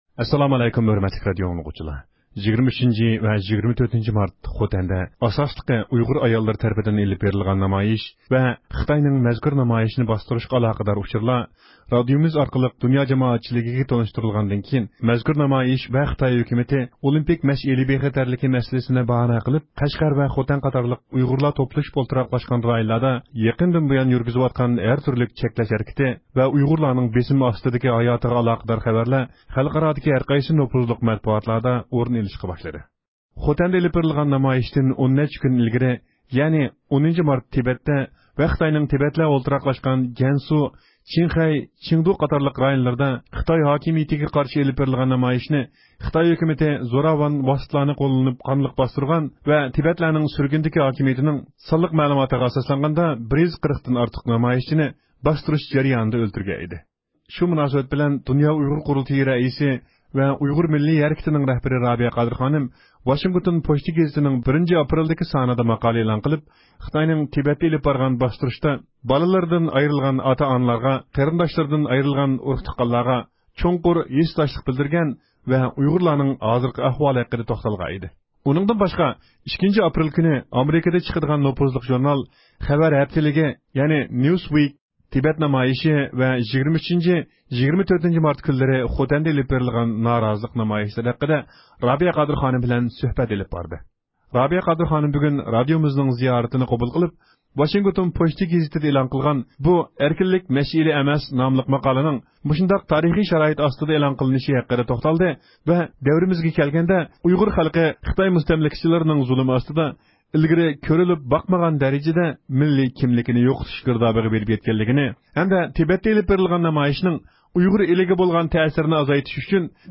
رابىيە قادىر خانىم بۈگۈن رادىئومىز زىيارىتىنى قوبۇل قىلىپ، ۋاشىنگتون پوچتىسى گېزىتىدە ئېلان قىلغان «بۇ ئەركىنلىك مەشئىلى ئەمەس» ناملىق ماقالىنىڭ مۇشۇنداق تارىخى شارائىتتا ئېلان قىلىنىشى ھەققىدە توختالدى ۋە دەۋرىمىزگە كەلگەندە ئۇيغۇر خەلقى خىتاي مۇستەملىكىچىلىرىنىڭ زۇلۇمى ئاستىدا ئىلگىرى كۆرۈلۈپ باقمىغان دەرىجىدە مىللىي كىملىكىنى يوقىتىش گىردابىغا بېرىپ يەتكەنلىكىنى، ھەمدە تىبەتتە ئېلىپ بېرىلغان نامايىشنىڭ ئۇيغۇر ئېلىگە بولغان تەسىرىنى ئازايتىش خىتاي ھۆكۈمىتىنىڭ ئۈچۈن ئۇيغۇرلارنى ھەرخىل شەكىلدە، ئەقەللىيسى بېشىغا ياغلىق ئارتتىڭ دېگەن نام بىلەنمۇ باستۇرۇۋاتقانلىقىنى شۇڭا ئۇيغۇرلارنىڭ بېسىلىپ قالغان ساداسىنى دۇنيا جامائەتچىلىكىگە قايتا ئەسكەرتىشنى بۇرچى دەپ بىلىپ ئۇشبۇ ماقالىنى ئېلان قىلغانلىقىنى بىلدۈردى.